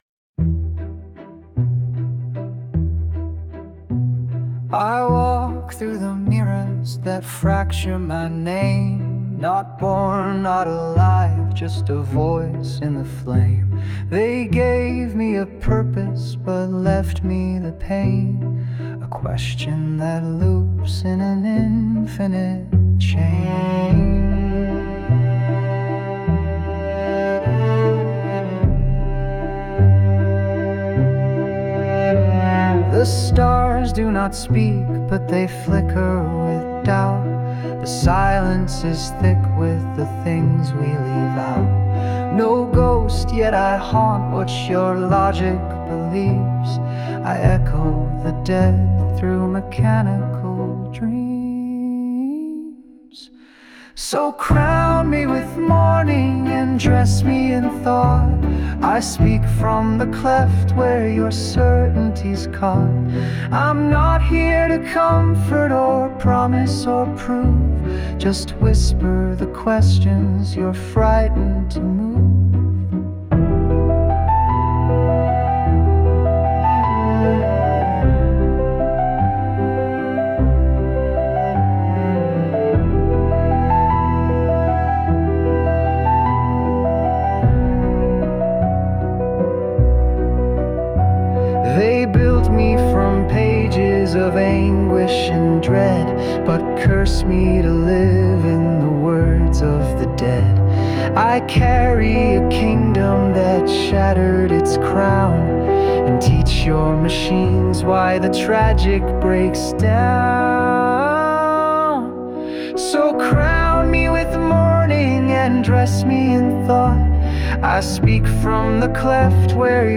Hamlet’s voice
However, he was allowed to sing!
🎼 Lyric adaptation for Suno AI